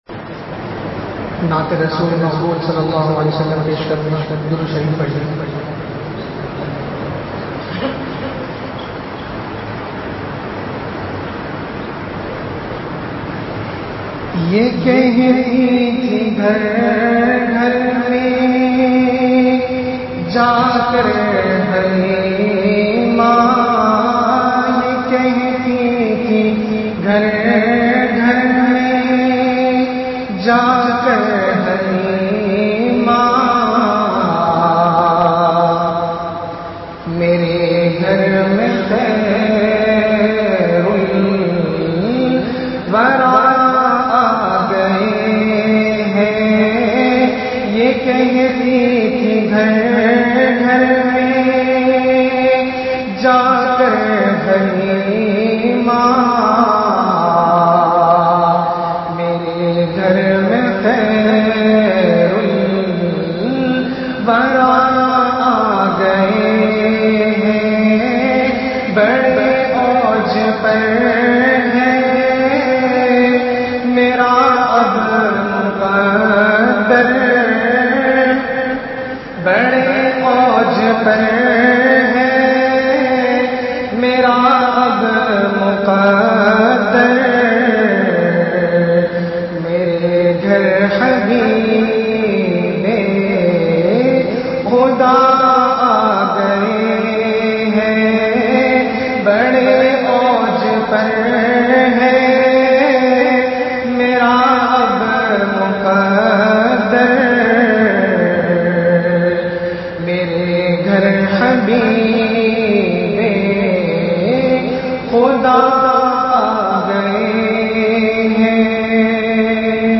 Bayanat
Tamam Preshanion ka 1 hi hal he allah or uske rasool s a w w ki taraf dor lagana (jummah byan Madni masjid Hedrabad )